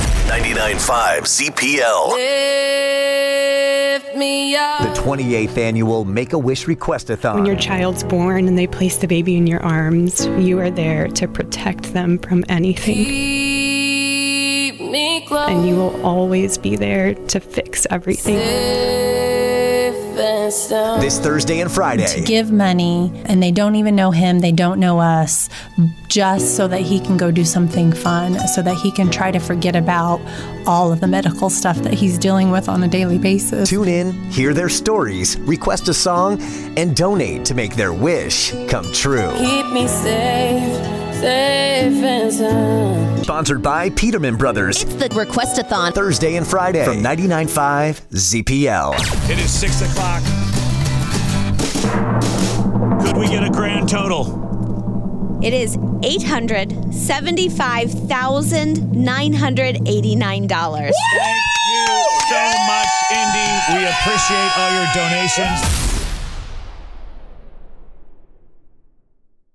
7. Best Radio Investigative ReportingWIKY-FM (Evansville) – Airport Shut Down